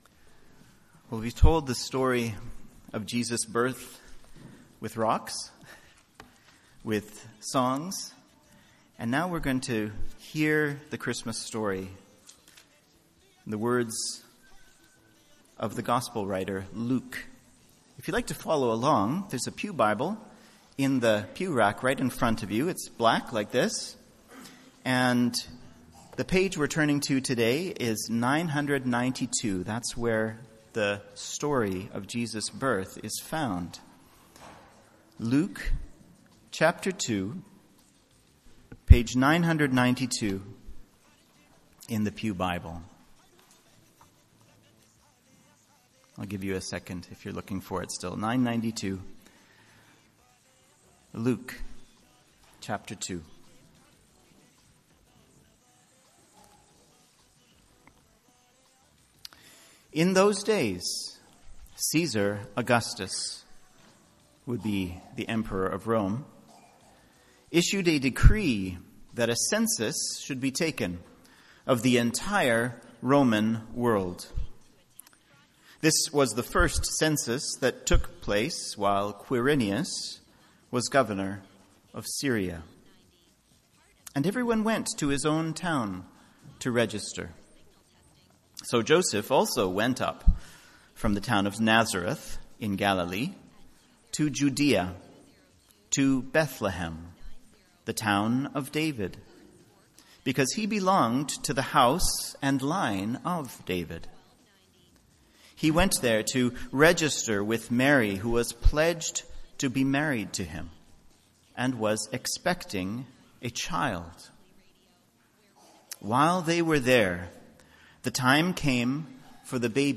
MP3 File Size: 19.5 MB Listen to Sermon: Download/Play Sermon MP3